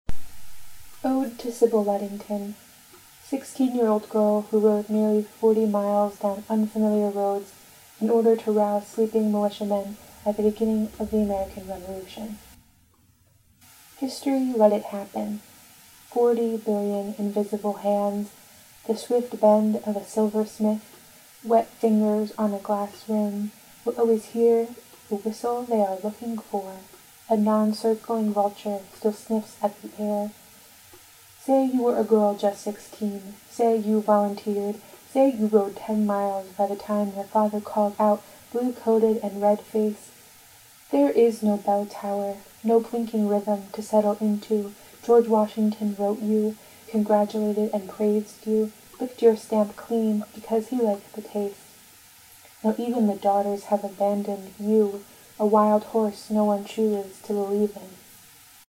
We put out a call to all poets in Issue to 6 to send us audio recordings of their poem and a number of poets did.